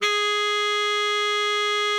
Added more instrument wavs
bari_sax_068.wav